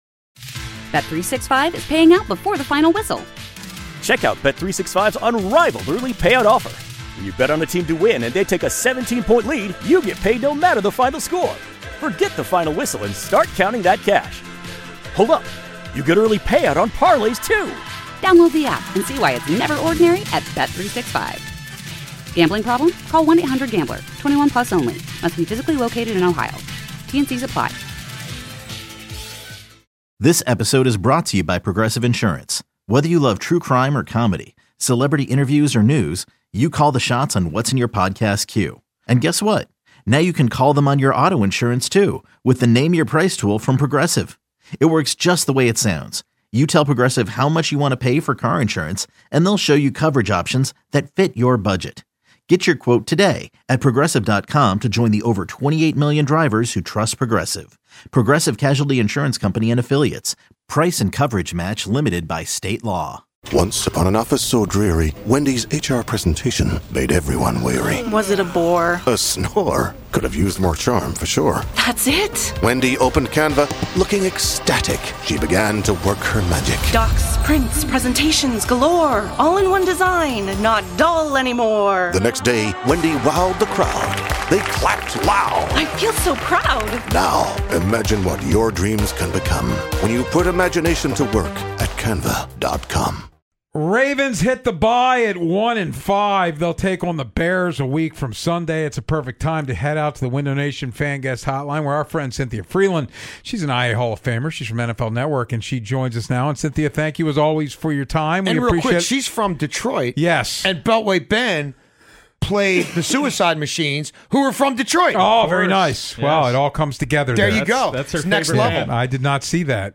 Data science analyst for NFL Network Cynthia Frelund joined the show to discuss the Ravens ugly start. She touches on if they could still make the playoffs, the Odafe Oweh trade, the Broncos chances to win the AFC, and much more.